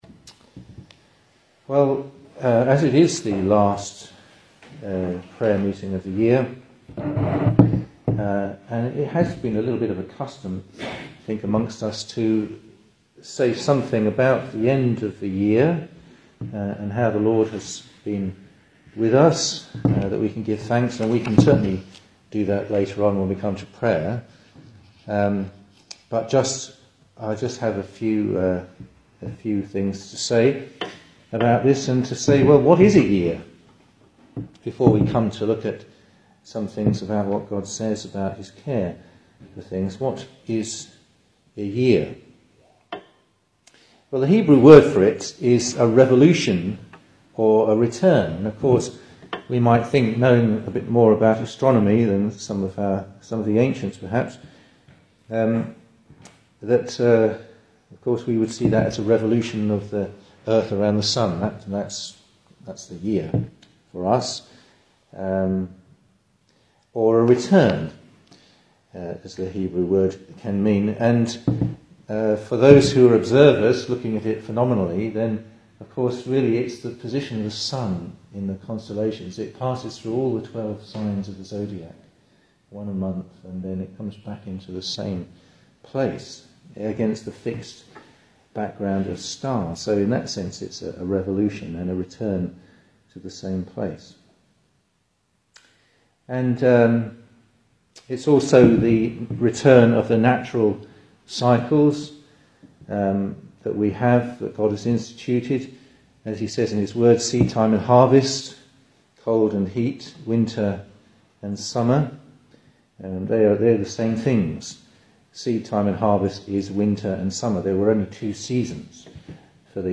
Service Type: Weekday Evening
Single Sermons